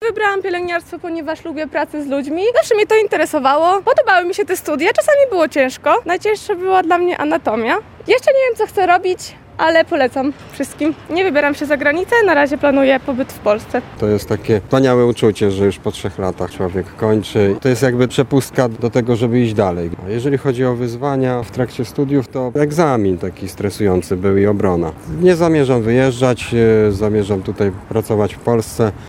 Zapytaliśmy absolwentów o wrażenia i plany na przyszłość.